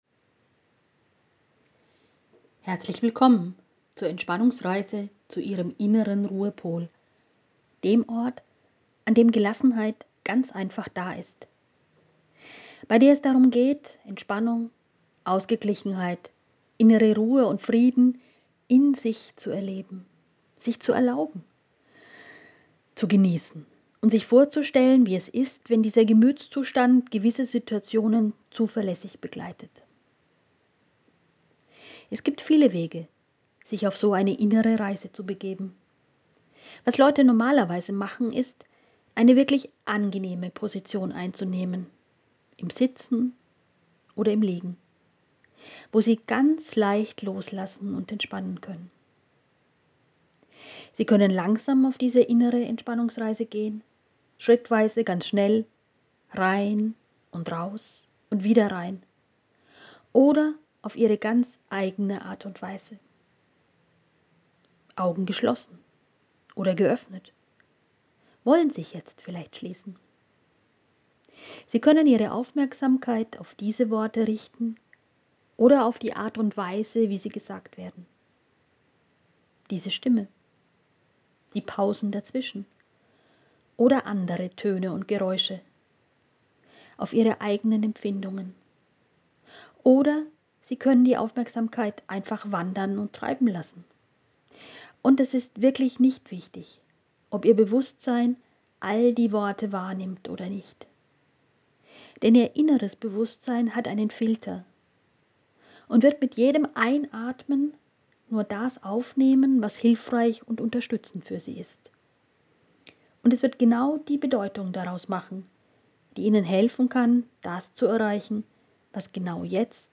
Das macht mental stark: Reisen zum inneren Ruhepol Gönnen Sie sich die nächsten 15 min eine Entspannungsreise zu Ihrem inneren Ruhepol – dem Ort, an dem Gelassenheit ganz einfach ist.
Entspannungsreise-zum-inneren-Ruhepol.wav